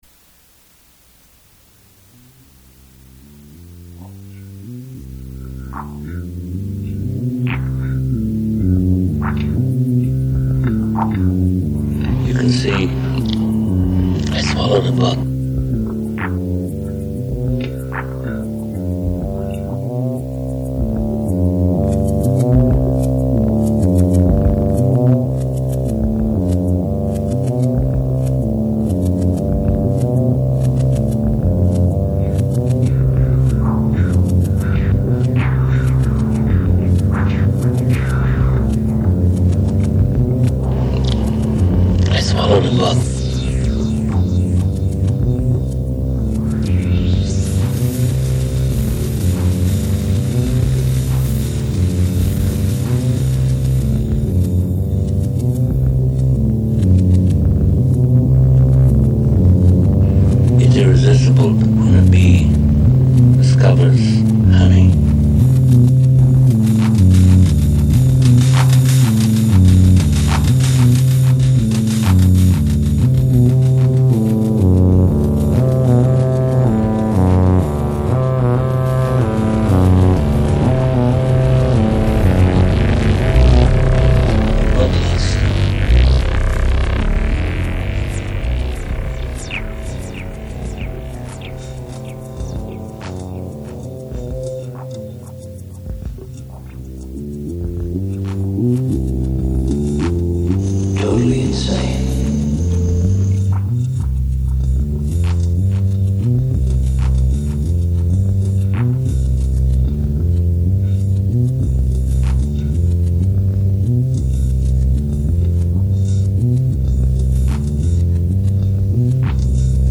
Dusty bullshit from the year 1999 made with Rebirth and Cool Edit Pro on my friends computer before i had any idea how to use those things
I think the drum patterns are 7 over 15…and then i clearly reversed something in cool edit.